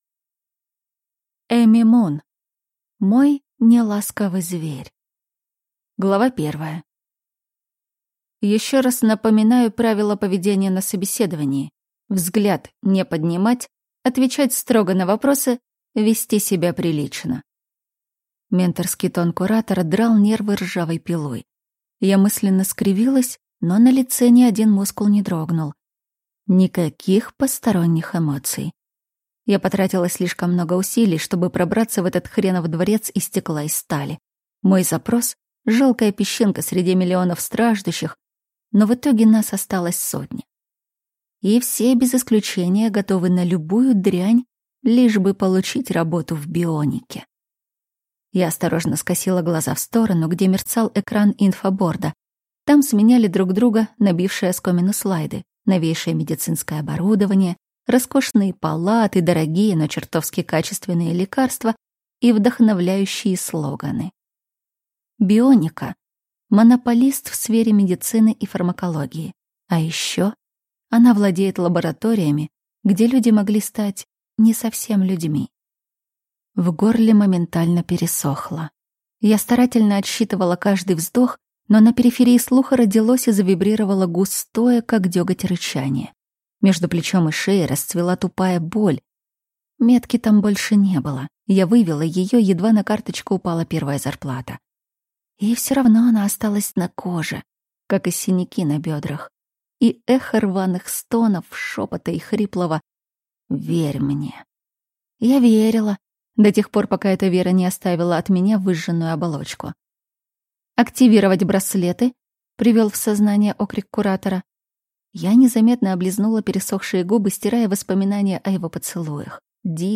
Аудиокнига Мой (не)ласковый зверь | Библиотека аудиокниг